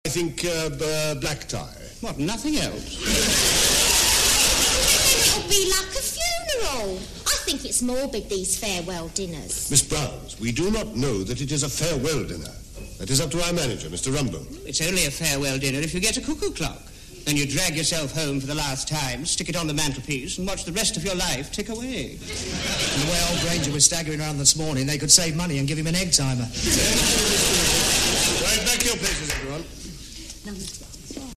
I did a filter using lowered mid range and got this result,not much better.